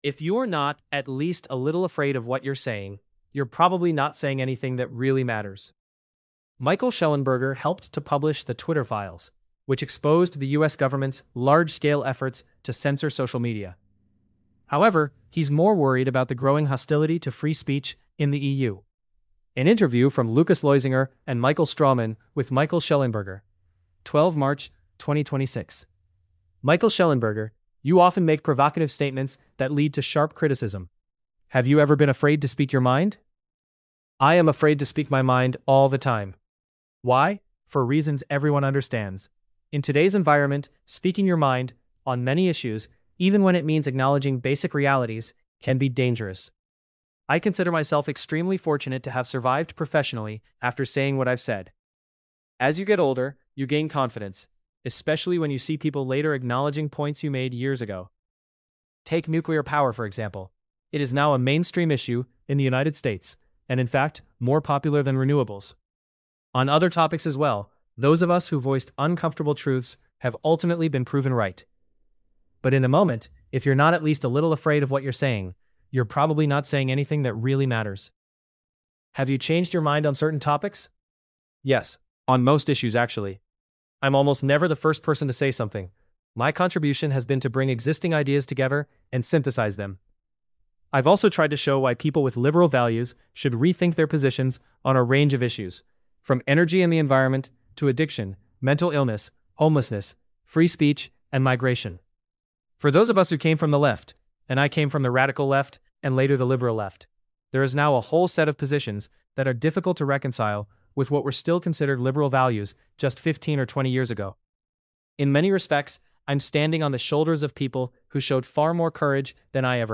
shellenberger_english_male.mp3